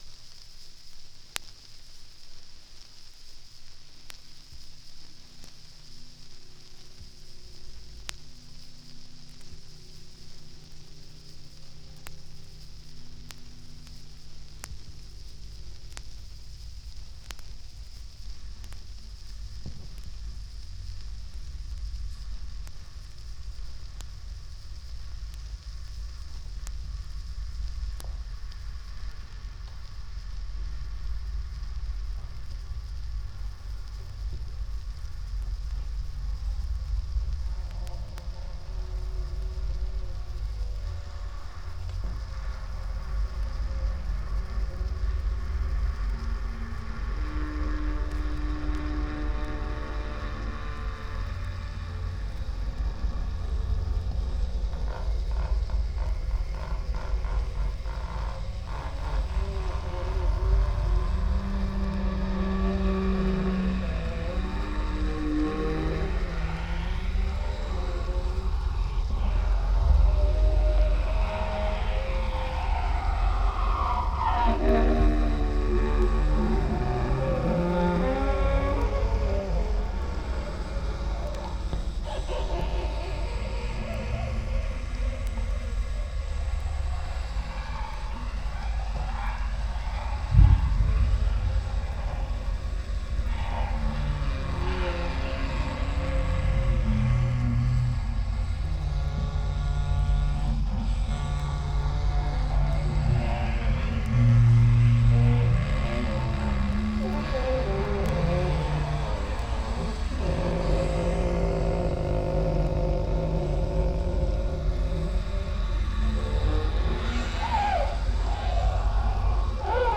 Vinyl digitalisiert mit:
1A1 01 bow on bow sextet, turn table (16 Kanal stereo) 17.03